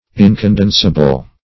Search Result for " incondensable" : The Collaborative International Dictionary of English v.0.48: Incondensable \In`con*den"sa*ble\, Incondensible \In`con*den"si*ble\, a. Not condensable; incapable of being made more dense or compact, or reduced to liquid form.